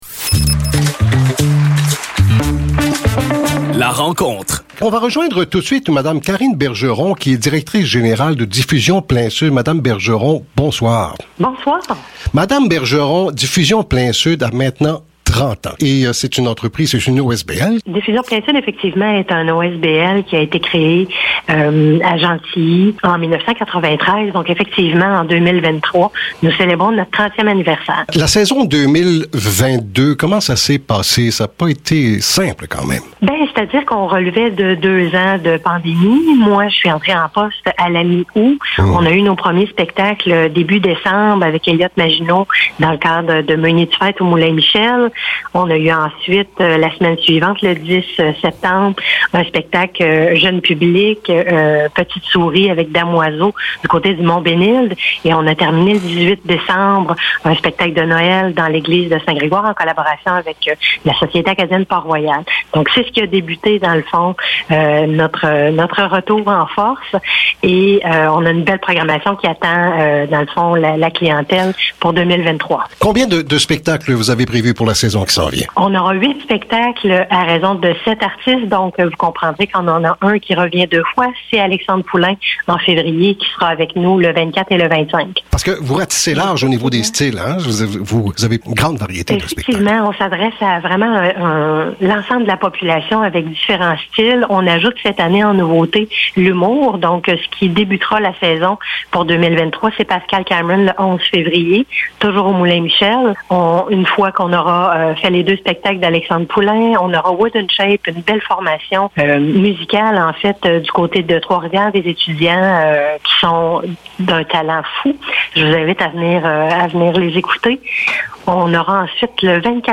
Plein-Sud-2023-entrevue-5-janv.mp3